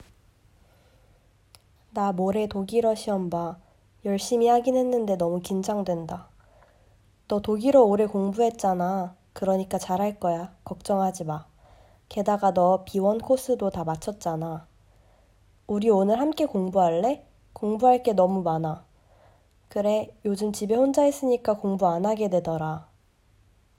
9_9th_week_Conversation_A.m4a